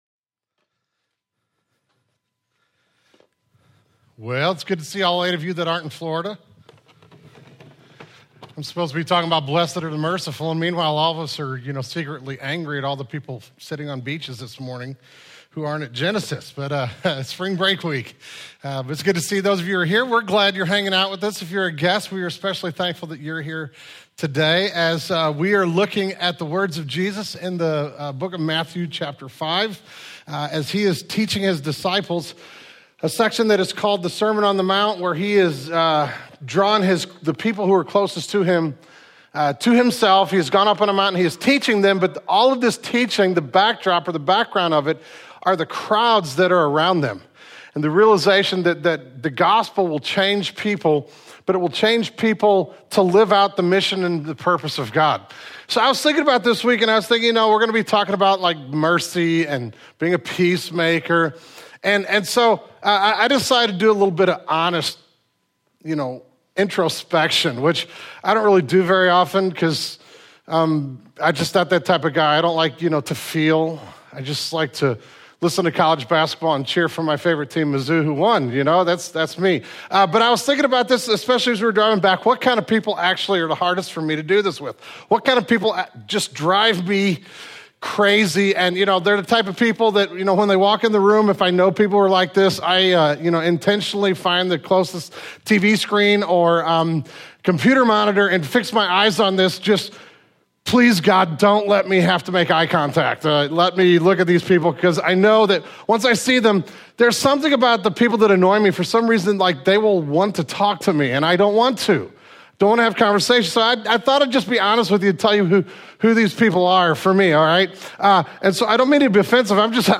Red Letters: Climbing the Character Ladder, Part 2 | Genesis Church | Eureka, MO 63025